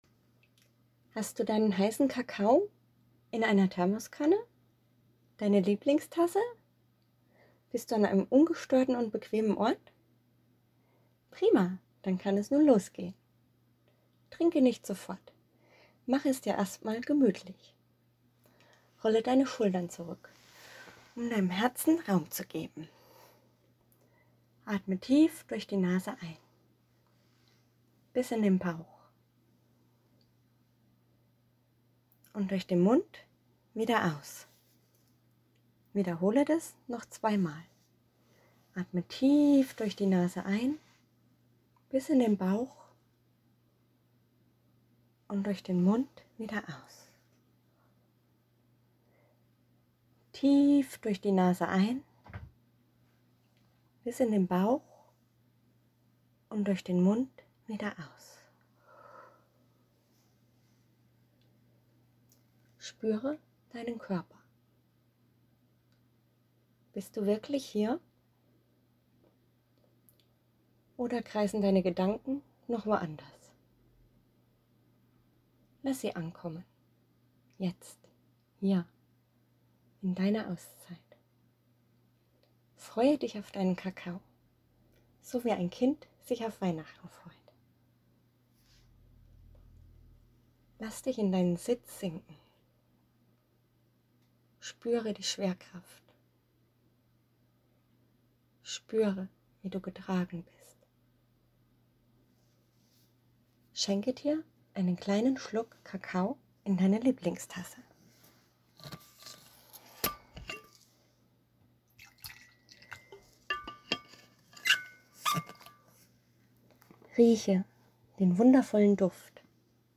cacao-meditation.mp3